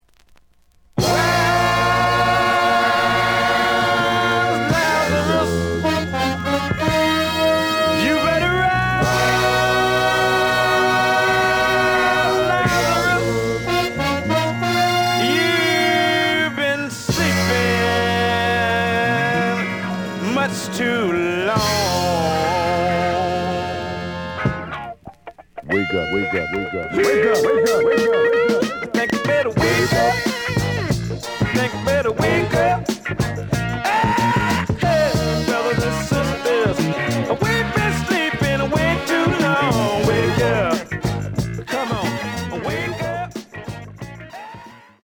試聴は実際のレコードから録音しています。
●Genre: Funk, 70's Funk